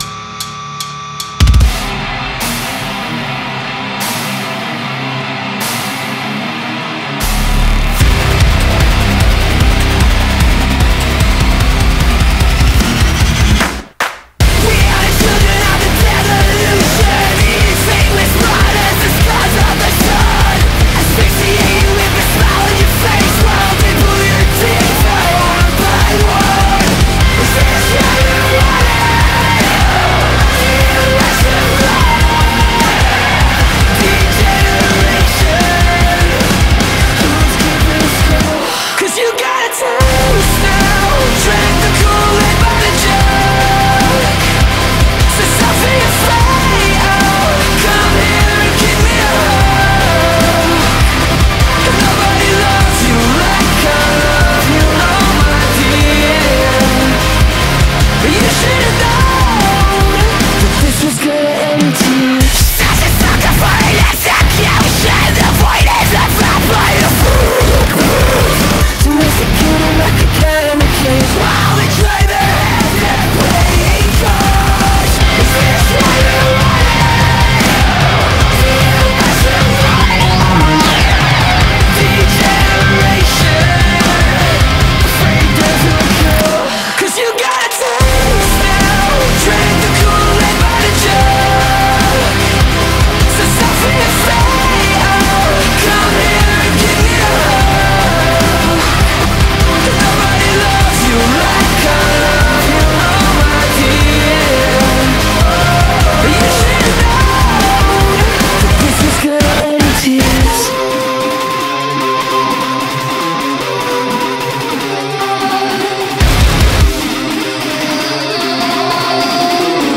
• Жанр: Rock, Metal